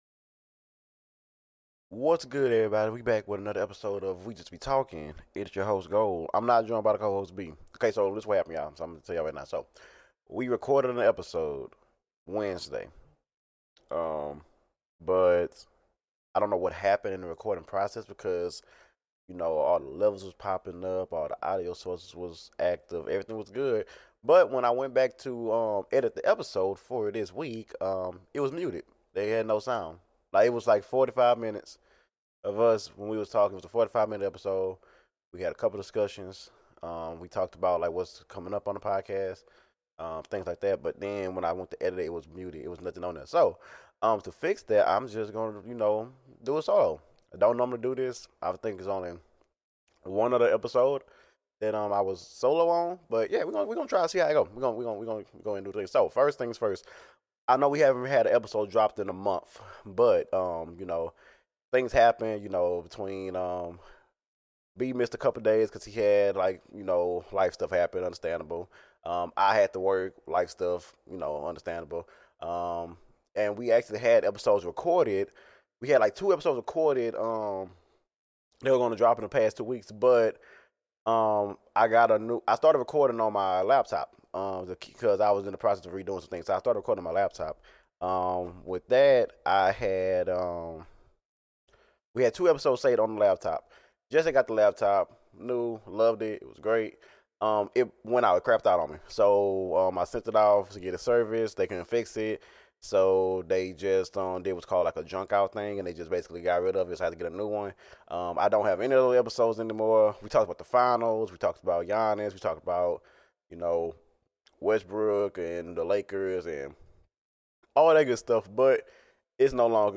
Solo episode